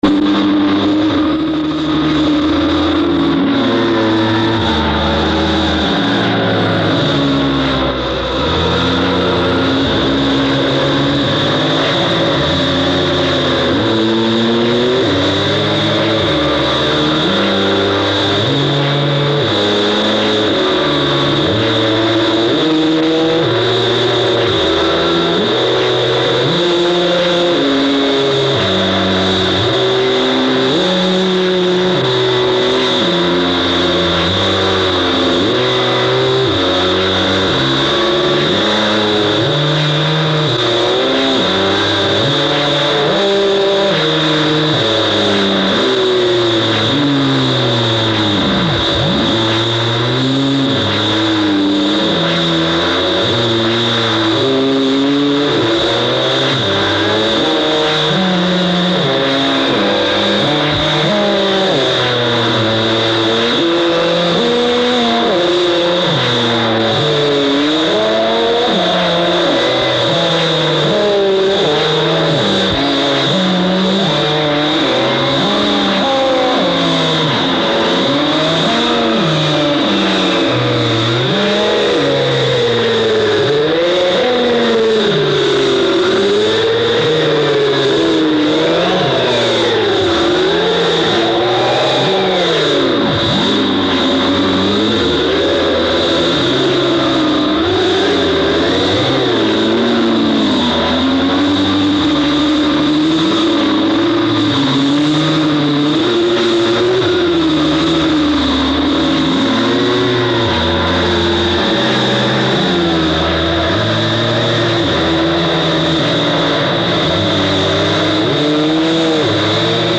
Remastered from original master tapes for superb audio.